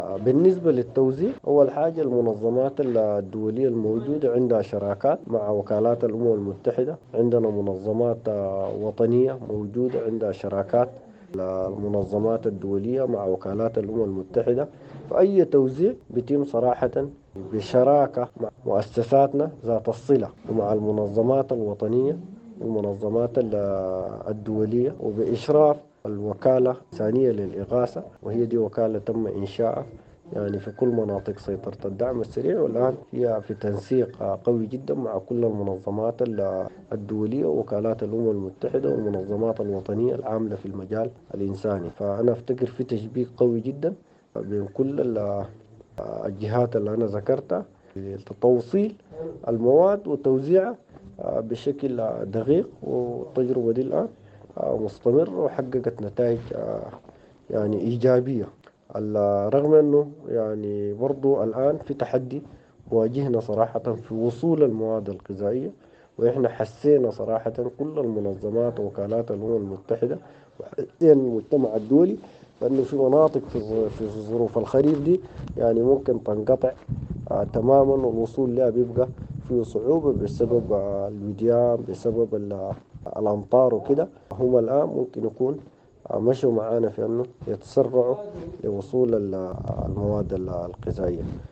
واكد رئيس الإدارة المدنية لولاية وسط دارفور، التجاني الطاهر كرشوم، في حوار مع “السودانية نيوز” ان التوزيع سيكون دقيقاً، لإيصال المساعدات الإنسانية لكل المناطق التي تقع تحت سيطرة الدعم السريع.